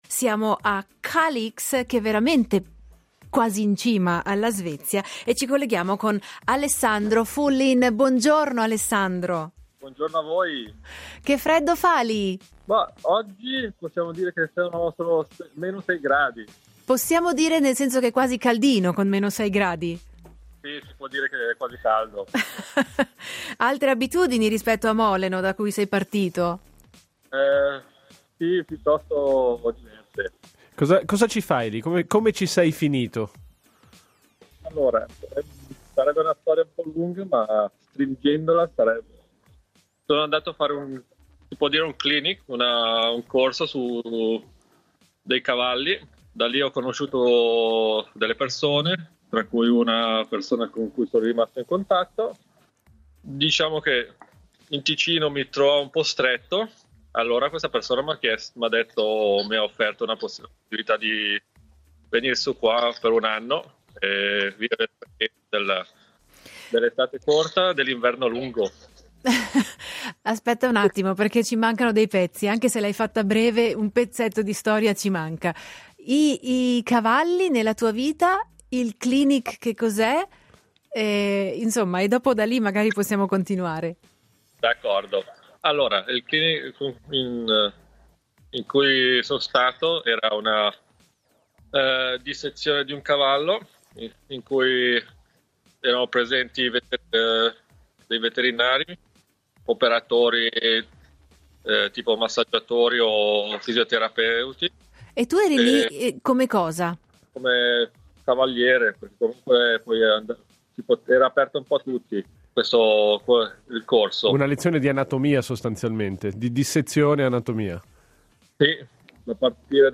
Questo ed altri misteri nella nostra chiacchierata di oggi